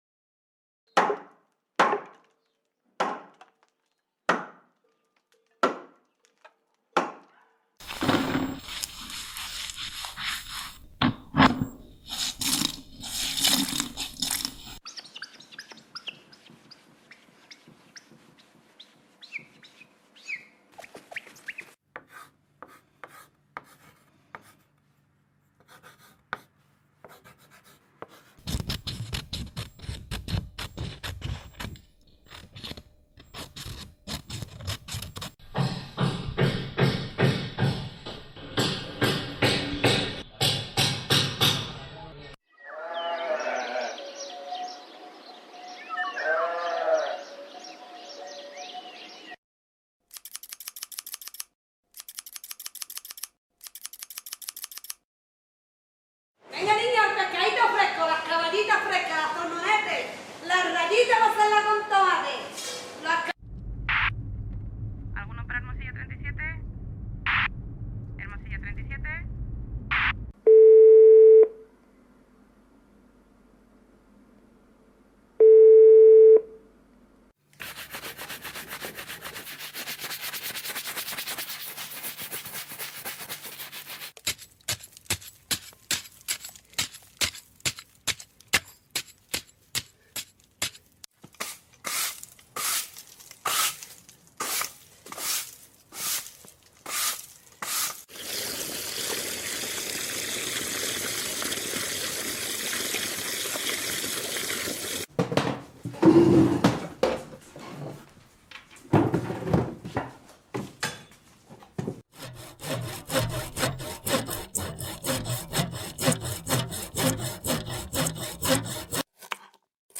Ara anem a jugar, escolteu només l’audio i intenteu endevinar quin ofici sona (us proposo anar apuntant el nom en un full i després ho comproveu amb el vídeo).